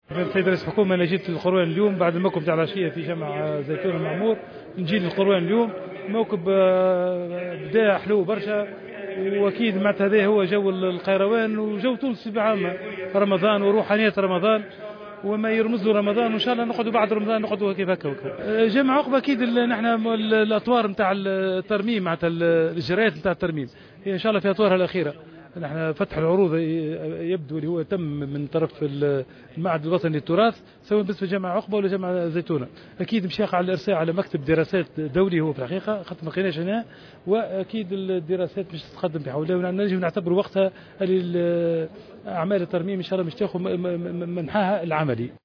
وأعلن عظوم بالمناسبة، في تصريح لمراسل الجوهرة أف أم، عن وصول الإجراءات التي تسبق عملية ترميم الجامع، إلى أطوارها الأخيرة، حيث تم تكليف المعهد الوطني للتراث بفتح طلب عروض سيقع بمقتضاه الإرساء على مكتب دراسات دولي في ظل عدم توفر مكتب محلي، على أن تنطلق الأشغال قريبا، بالتوازي مع أشغال ترميم جمع الزيتونة.